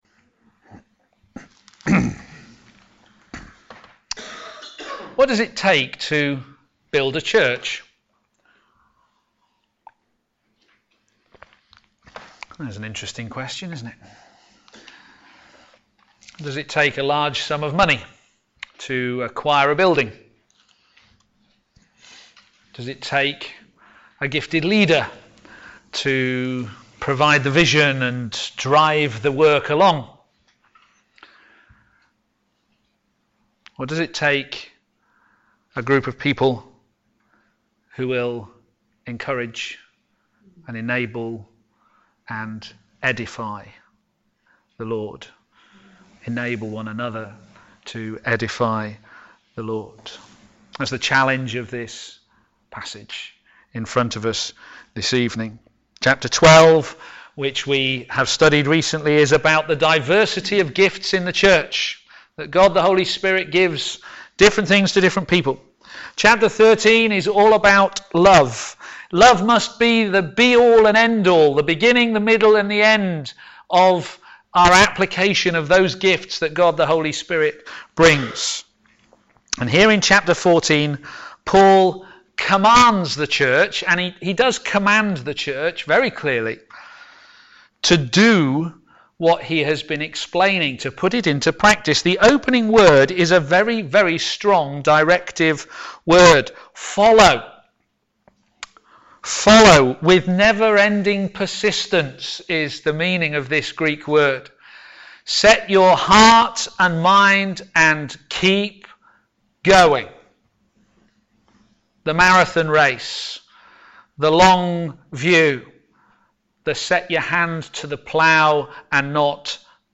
p.m. Service
Series: Working Together to Advance the Gospel Theme: Prophecy is better than tongues Sermon